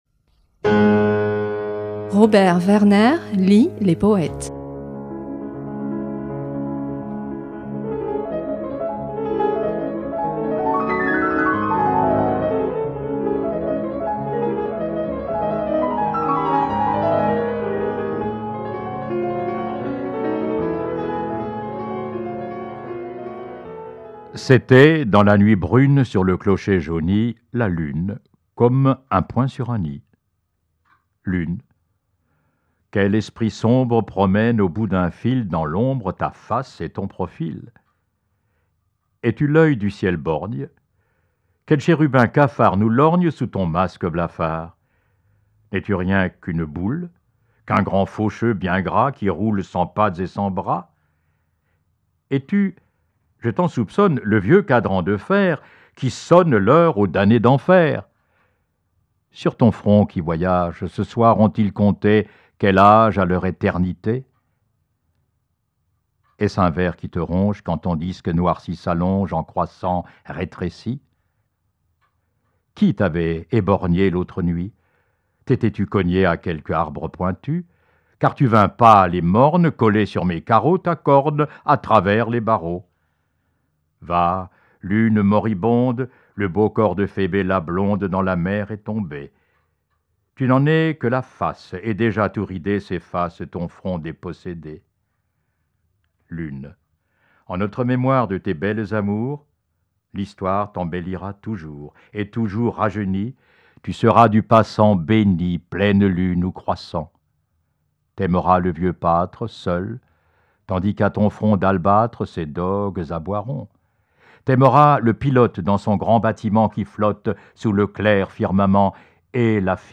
lit cette semaine Ballade à la lune, poème d’Alfred de Musset (1810-1857)